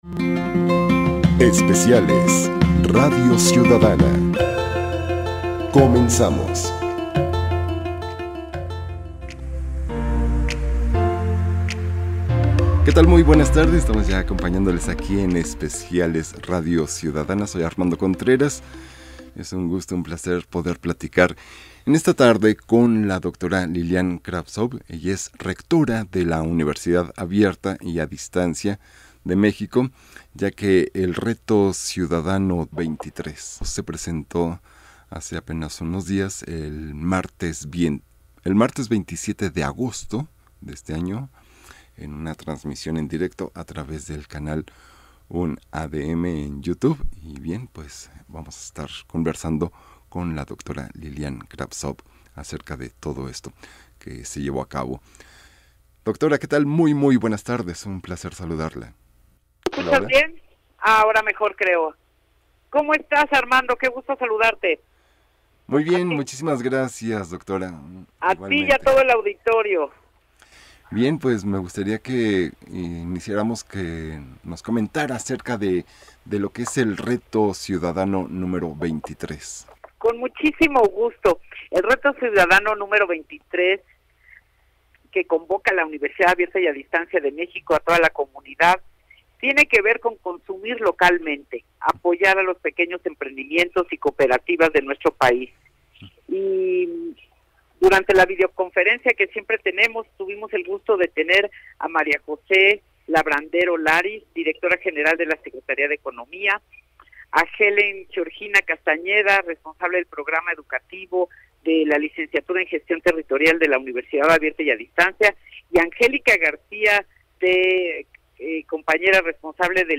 En Radio Ciudadana 1350 AM.
entrevista_IMER_Reto_23.mp3